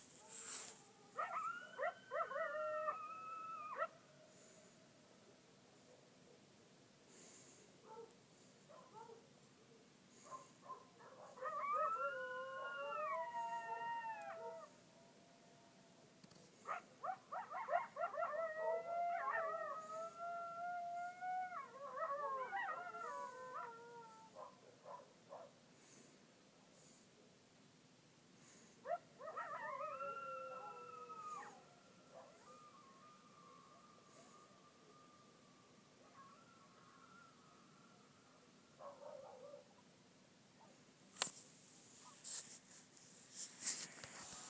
The day … (updated with coyotes)
Quiet … until after dark, when the coyotes came out. Click here for coyotes (and a few disgruntled dogs and coyote echo on down the creek):